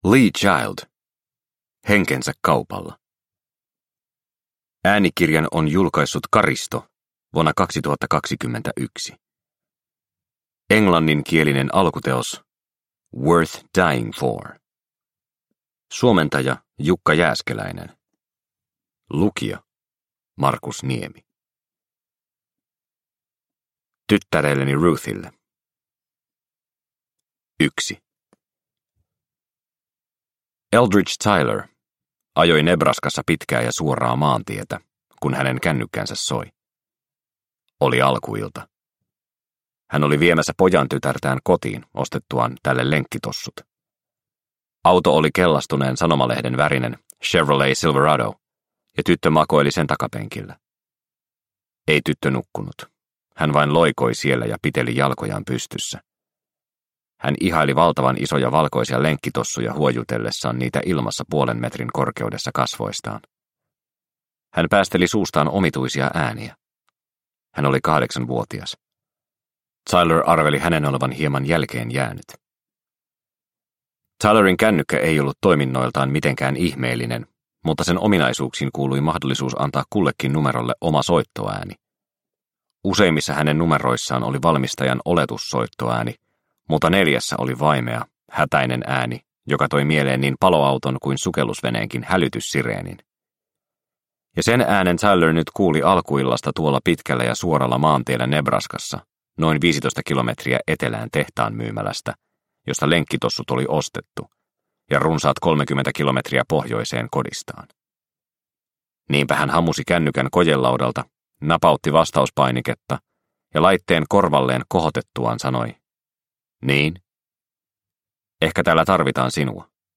Henkensä kaupalla – Ljudbok – Laddas ner